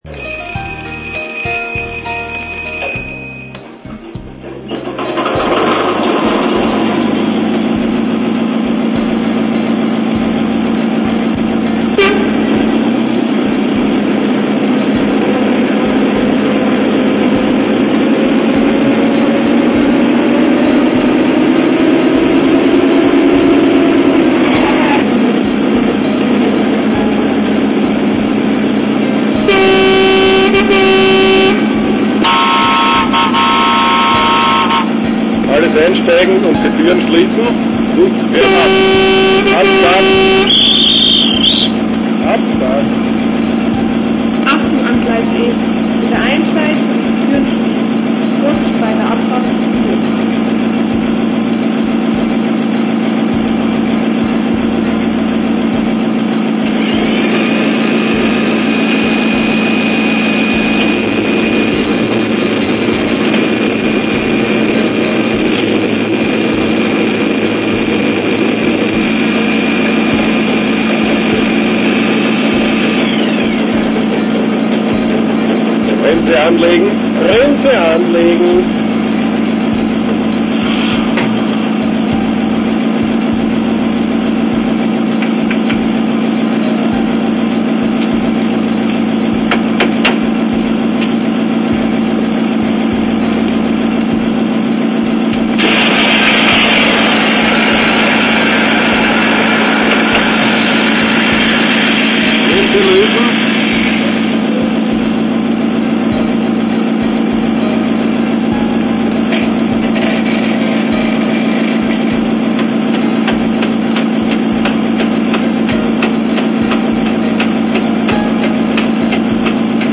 Der eMOTION XLS Sounddecoder gibt die authentischen Geräusche einer Lokomotive in hochwertiger digitaler Qualität wieder. Hierzu werden Soundaufnahmen direkt am Vorbild vorgenommen und dann im Soundlabor für die Elektronik abgeglichen.
• Standgeräusch
• Fahrgeräusch
Die Hintergrundmusik in den MP3-Demo Dateien ist nicht im XLS-Modul vorhanden!
Soundgeräusch